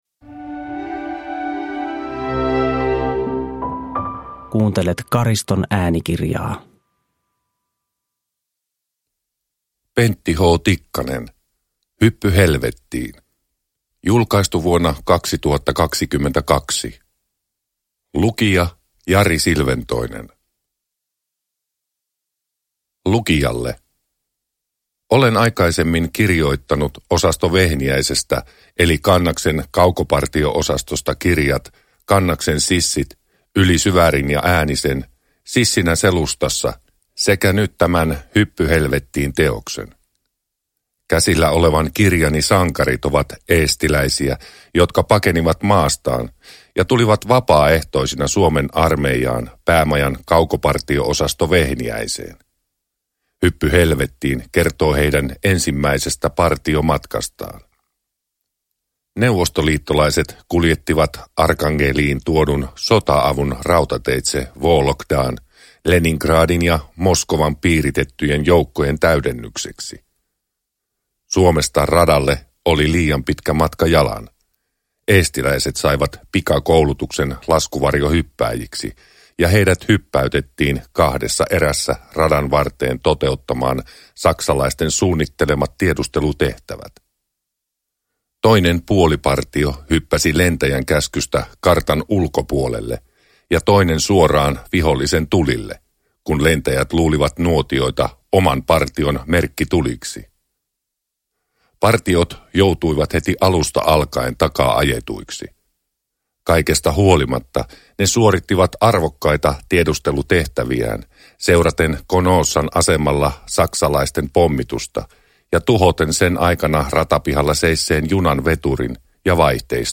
Hyppy helvettiin – Ljudbok – Laddas ner